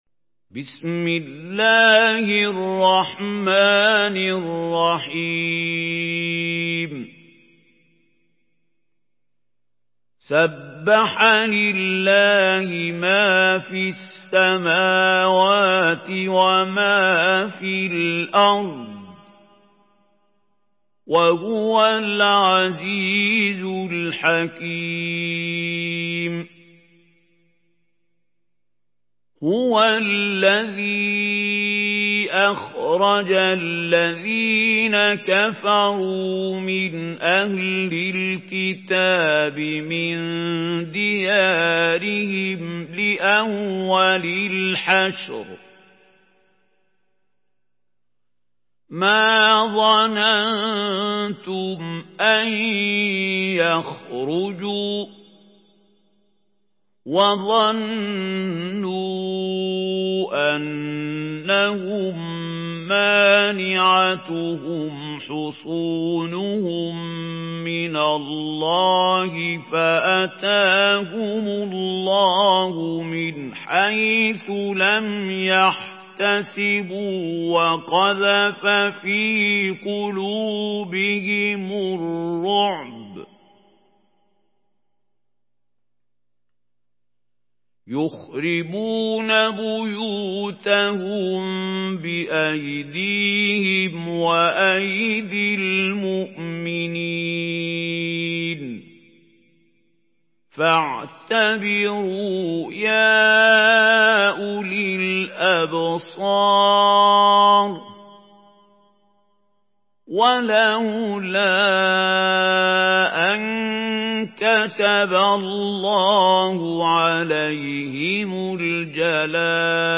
Surah Al-Hashr MP3 by Mahmoud Khalil Al-Hussary in Hafs An Asim narration.
Murattal Hafs An Asim